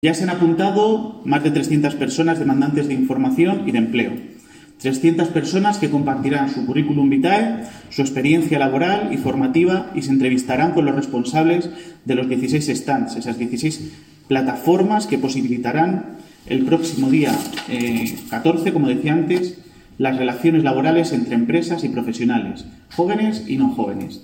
Declaraciones del alcalde Miguel Óscar Aparicio 1